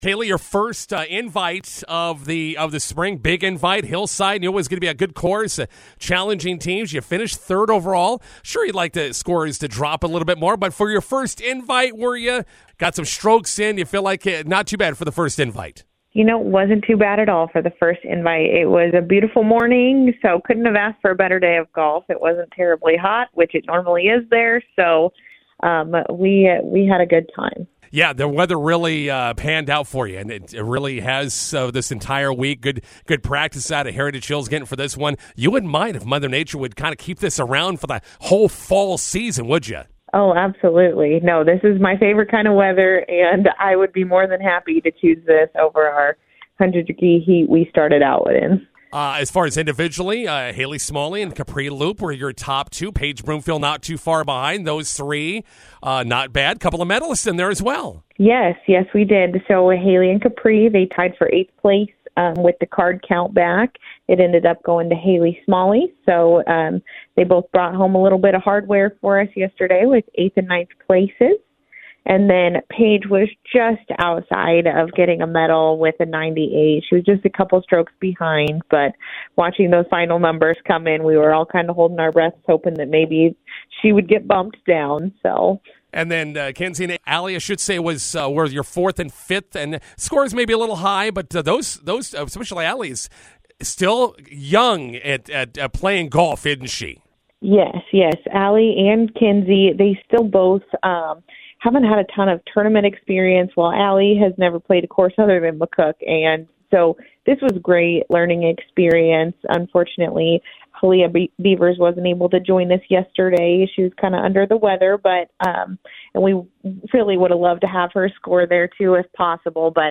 INTERVIEW: Bison golfers finish third at Sidney Invite on Tuesday.